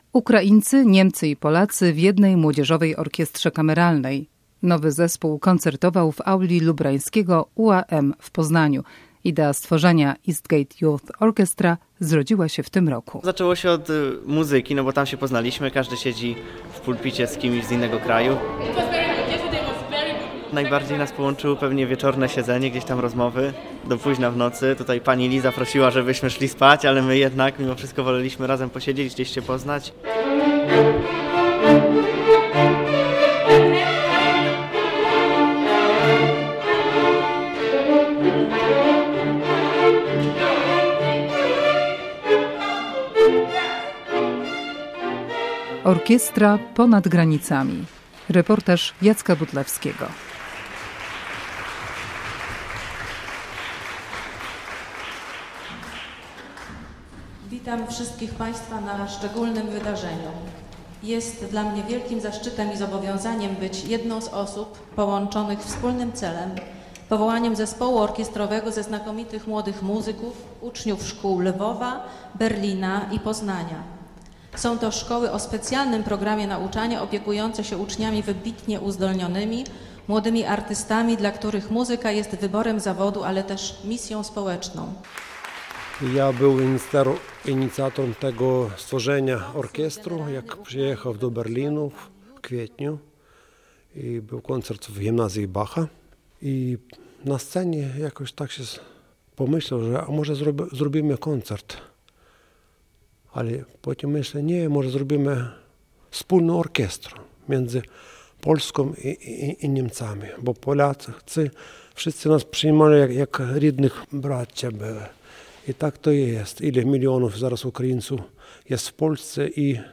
Pobierz plik Reportaż w Radio Poznań o EYO Rozmiar: 16.36MB Format: mp3